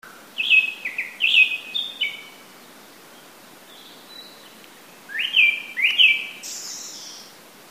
クロツグミ
クロツグミ 2007年6月2日 野鳥 ハイライトの緑をバックに 歌うその声 に聞きほれる。